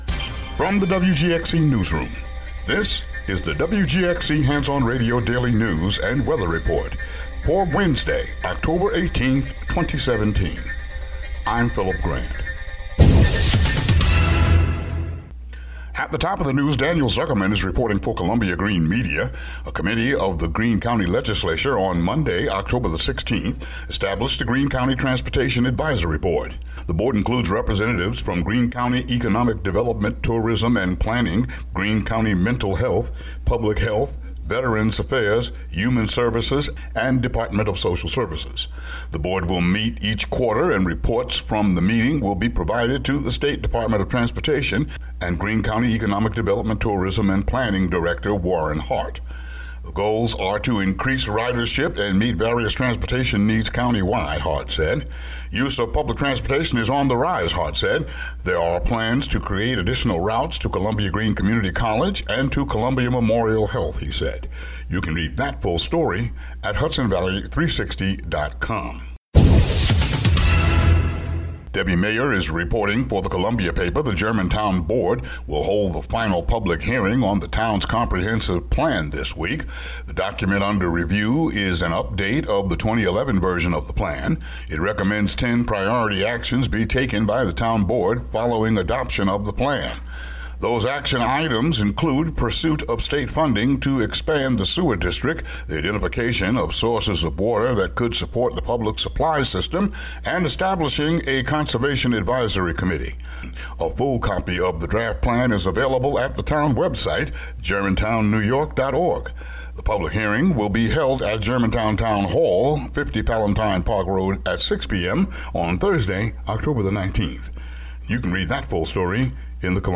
WGXC daily headlines for Oct. 18, 2017.